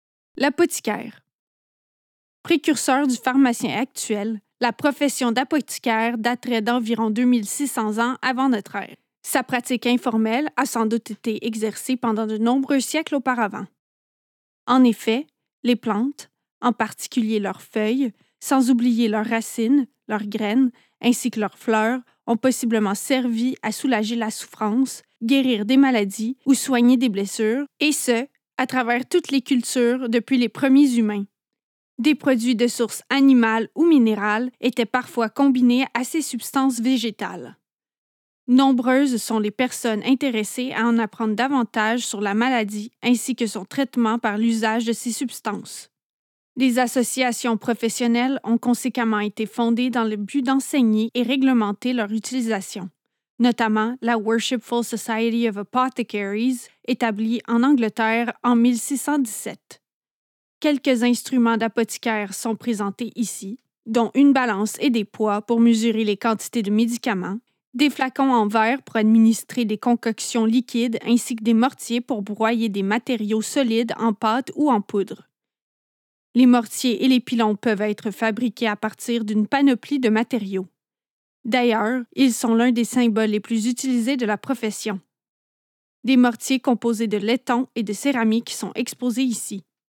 Below are audio guides for the artifacts displayed.